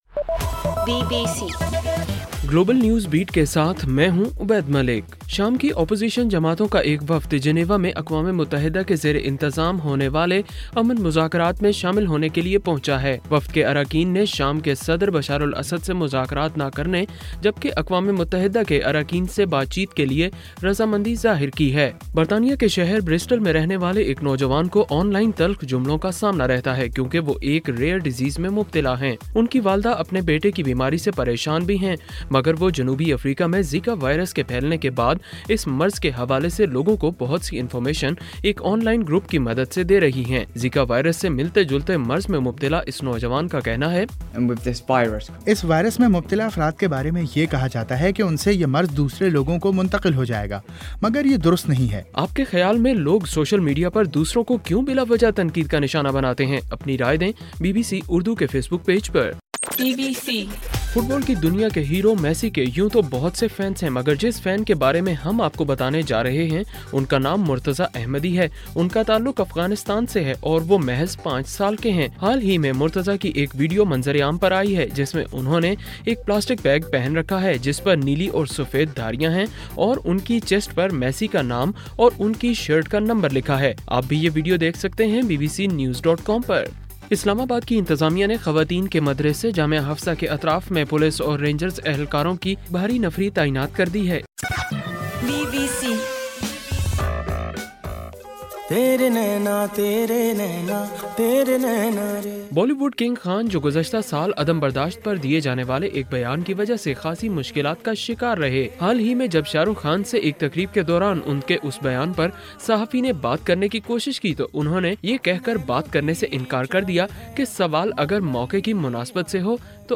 جنوری 31: صبح 1 بجے کا گلوبل نیوز بیٹ بُلیٹن